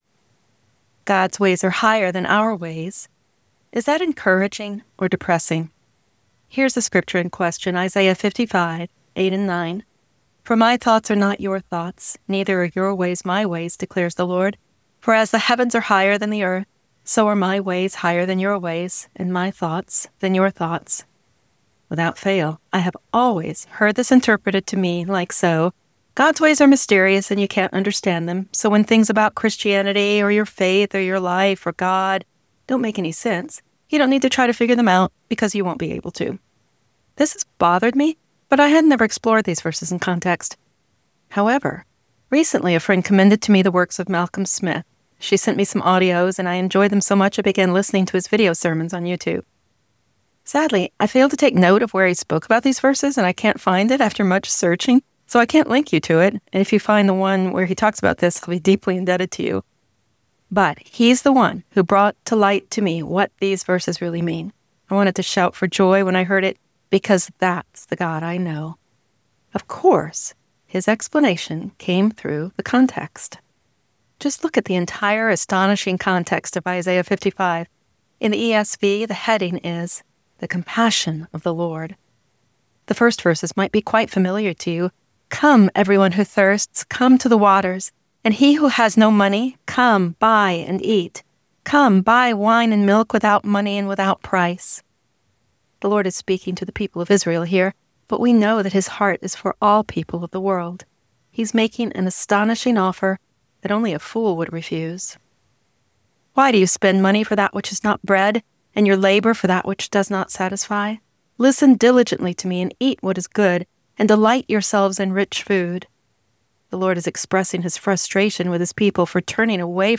You can listen to me reading this article here: